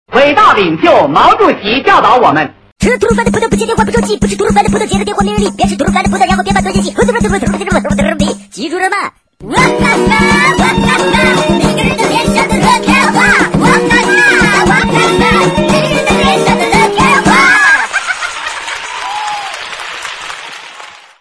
MP3铃声